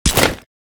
crack_wolf1.ogg